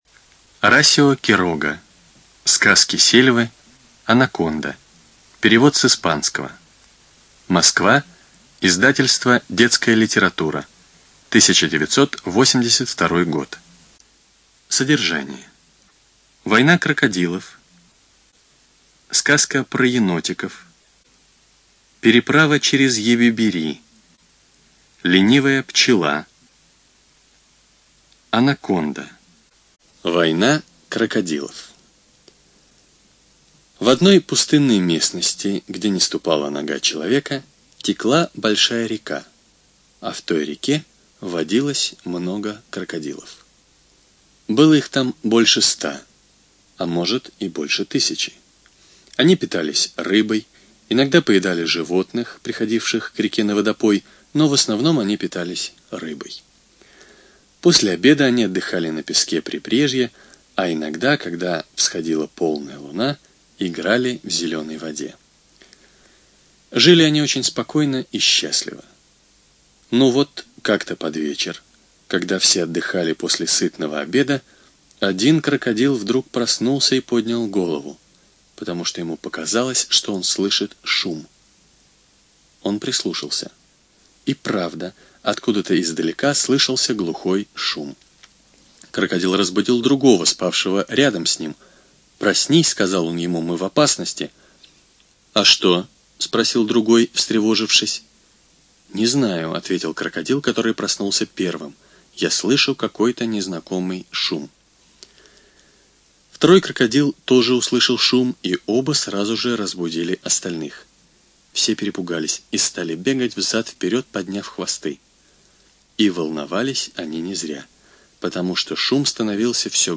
ЖанрДетская литература, Сказки
Студия звукозаписиЛогосвос